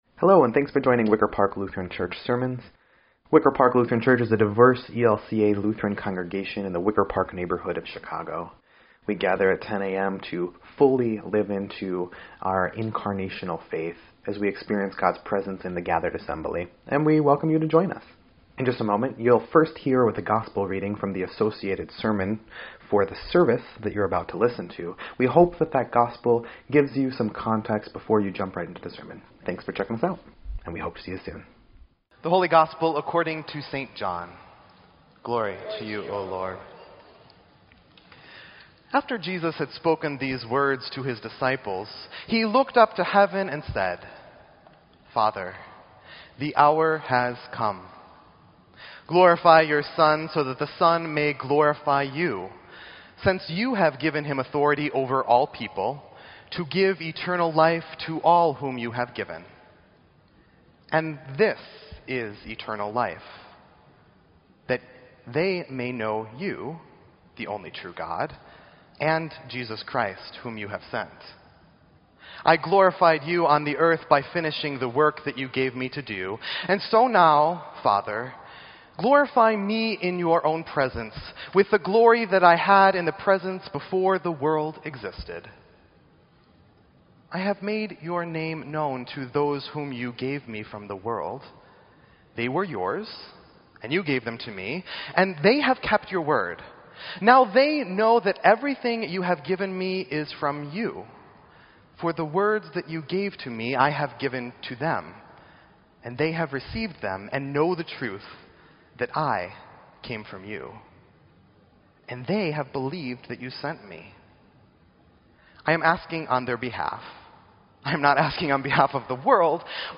Sermon_5_28_17_EDIT.mp3